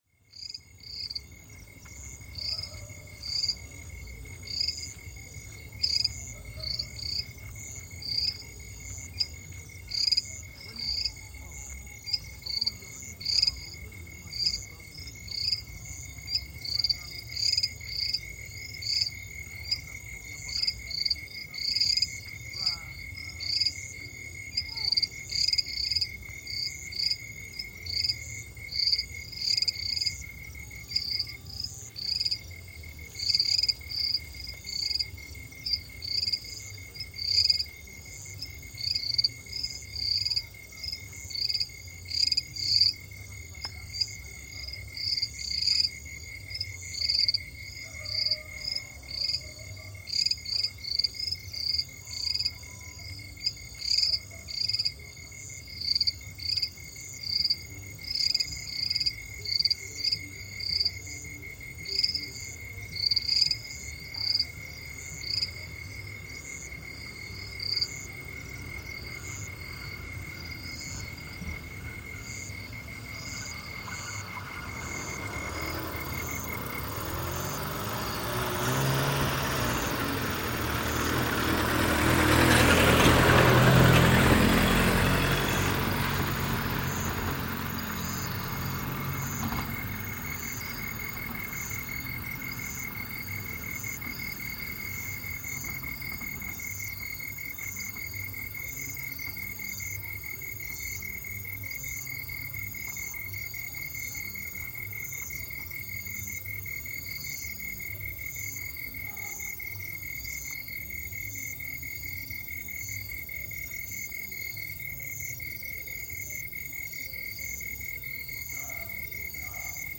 Insect and amphibian voices by a roadside in Ghana
Melodious and pulsating calls and voices of insects and frogs by the side of a road under construction in the Bonya community in the year 2021. Occasional autos, human voices, and light music can be heard far and near.